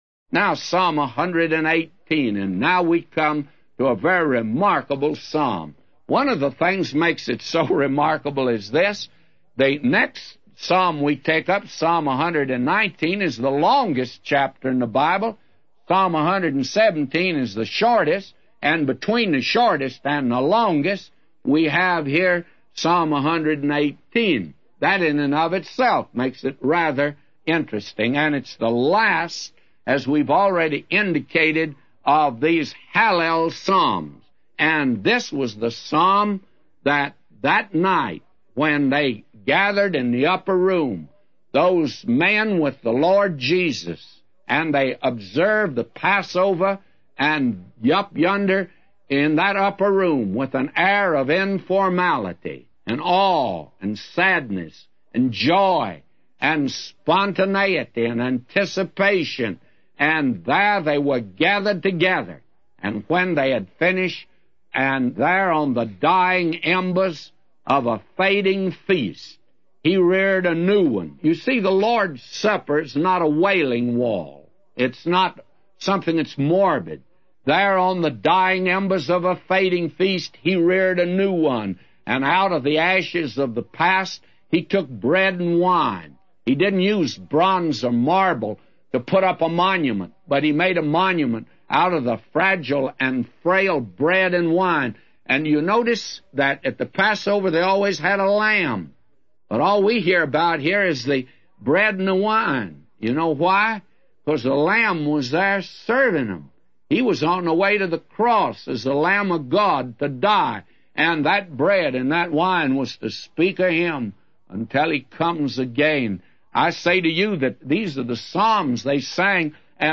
A Commentary By J Vernon MCgee For Psalms 118:1-999